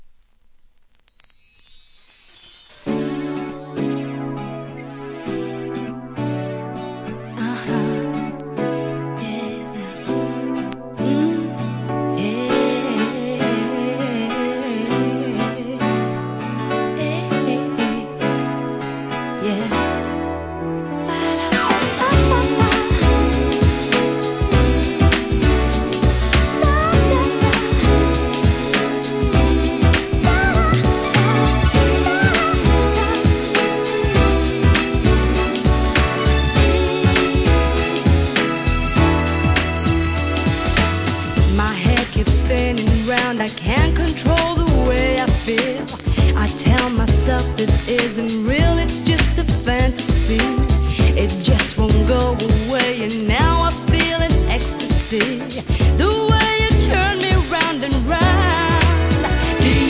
HIP HOP/R&B
キャッチーかつグルーヴィなレア音源を収録したコンピシリーズ、第2弾！！